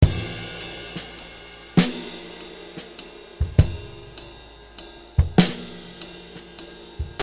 SAMPLES DE BATERIA
¿Te gustan los samplers de ritmos y redobles de batería?, aquí tengo unos cuantos bajados de Internet a tu disposición en formato mp3.
6_8 Loop 13.MP3